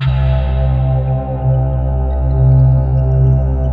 Index of /90_sSampleCDs/USB Soundscan vol.28 - Choir Acoustic & Synth [AKAI] 1CD/Partition C/16-NIMBUSSE